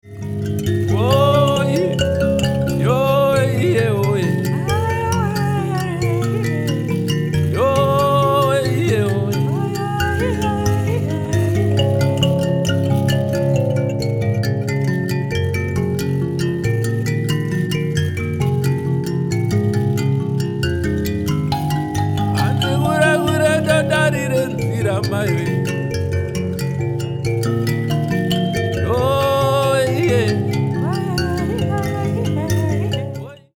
it was recorded in an incredible recording studio
nemakonde tuning